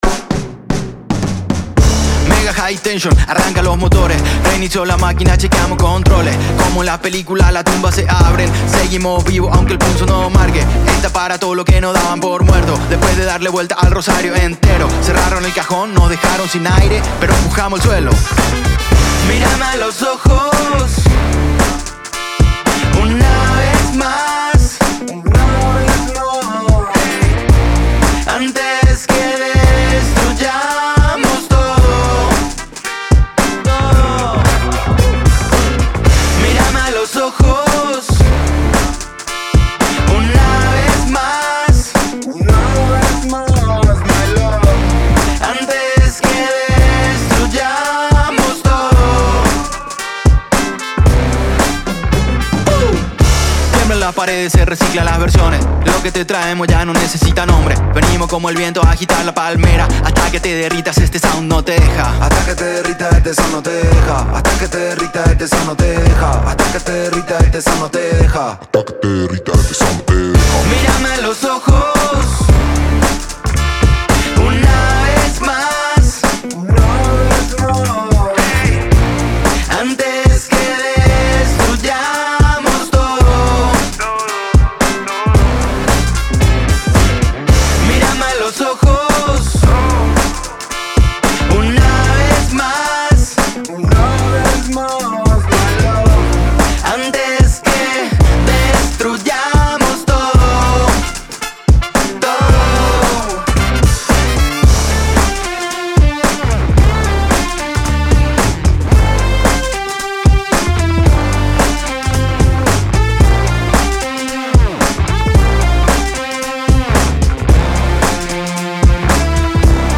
trompeta